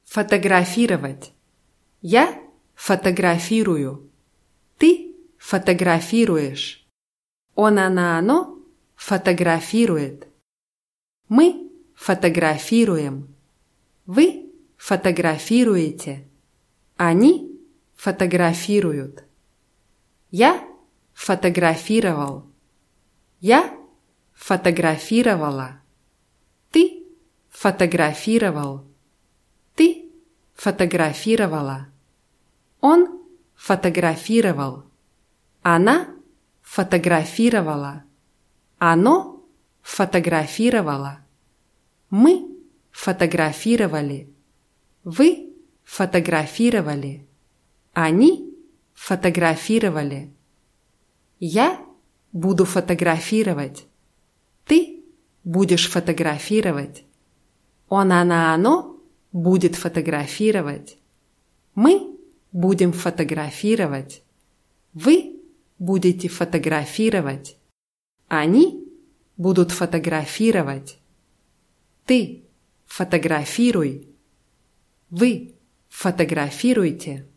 фотографировать [fatagrafʲírawatʲ]